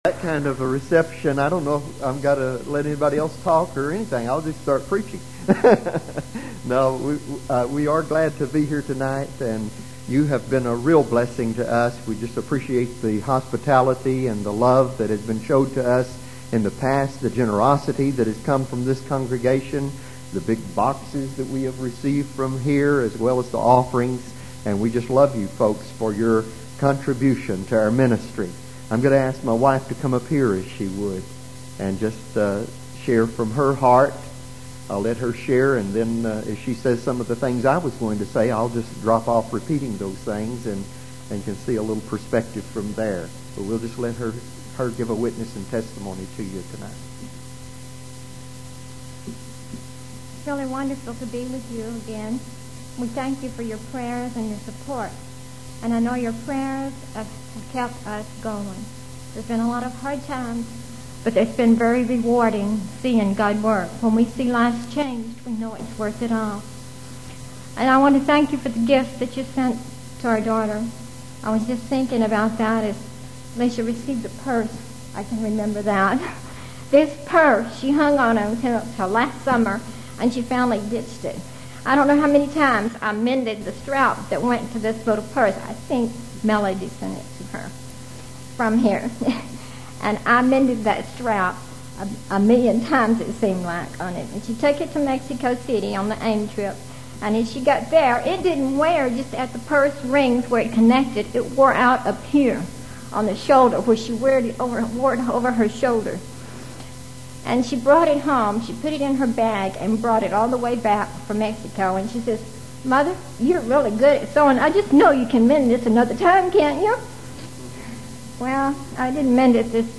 All Sermons on Pentecostal Gold are the exclusive property of the copyright owner.